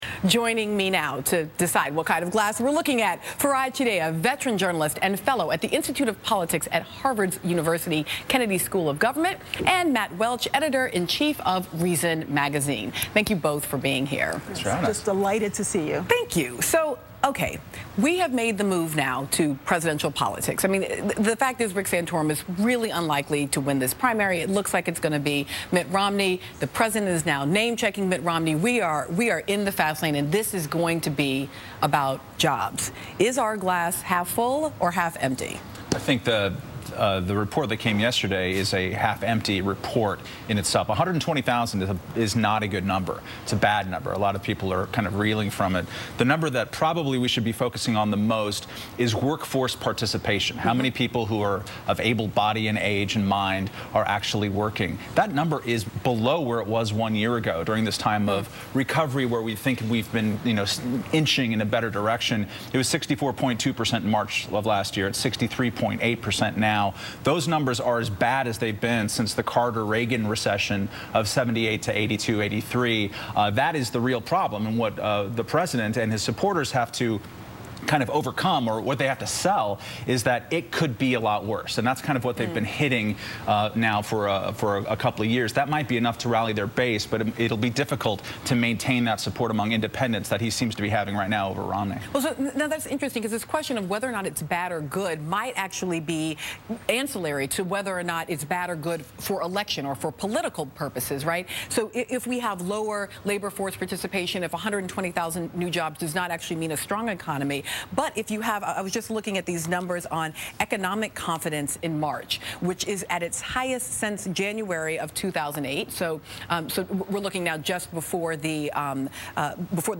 Reason Editor in Chief Matt Welch appeared on MSNBC's Melissa Harris-Perry Show to discuss today's most heated political battles - Obama vs Romney on the economy, Obama vs SCOTUS on healthcare, & SCOTUS vs the public's privacy on strip searches.